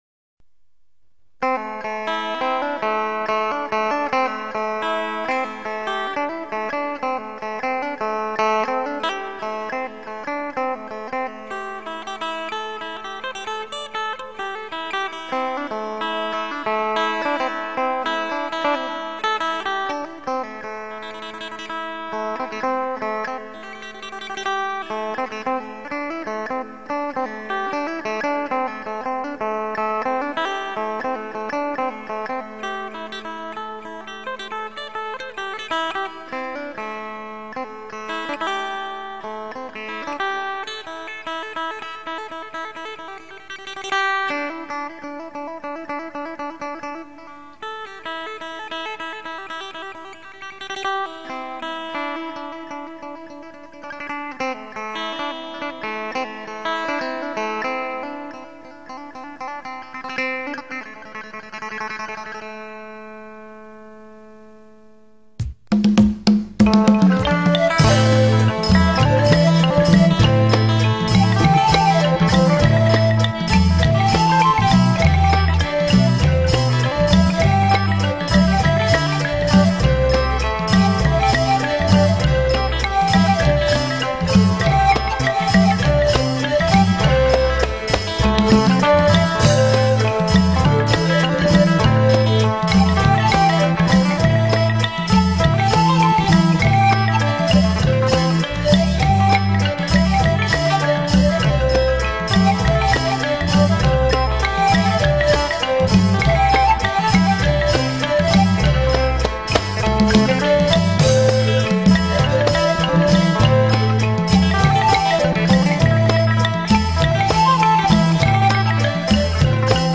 ลายเต้ย 3 จังหวะ.mp3